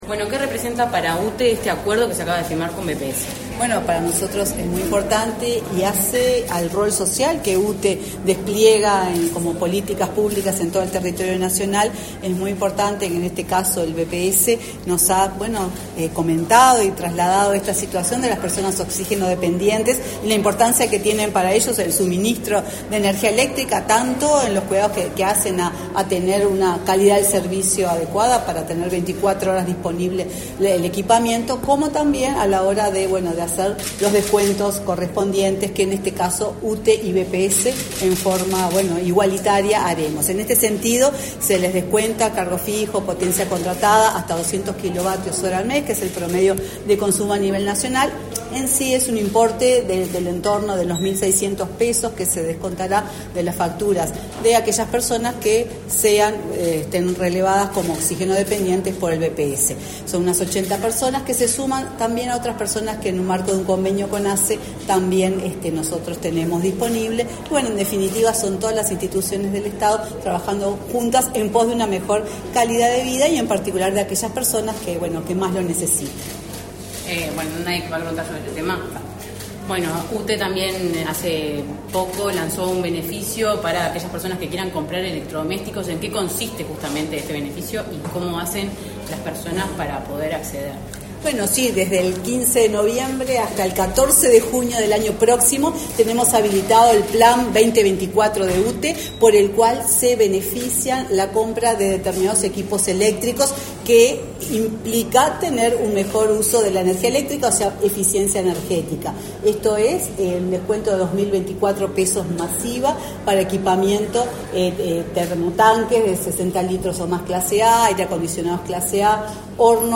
Declaraciones a la prensa de la presidenta de UTE, Silvia Emaldi